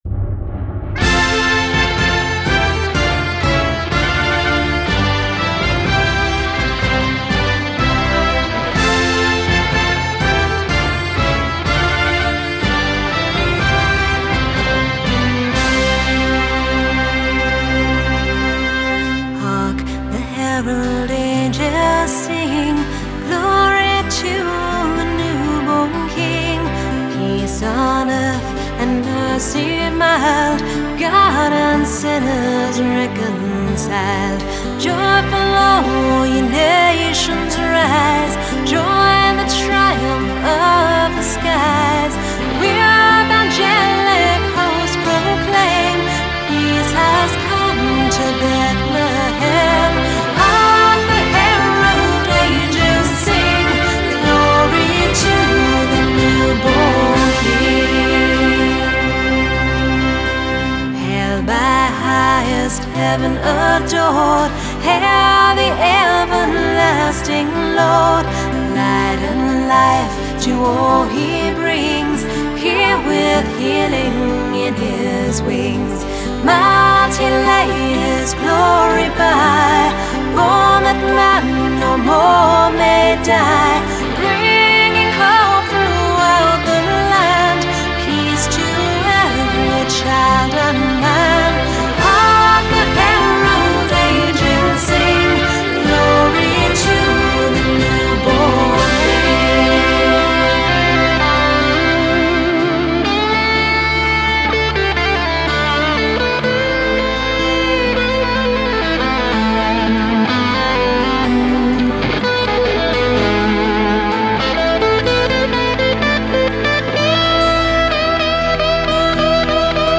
a digitally remastered version of their original classic.
guitarist
Mastering the mix of rock and folk elements